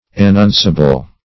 Meaning of annunciable. annunciable synonyms, pronunciation, spelling and more from Free Dictionary.
Search Result for " annunciable" : The Collaborative International Dictionary of English v.0.48: Annunciable \An*nun"ci*a*ble\, a. That may be announced or declared; declarable.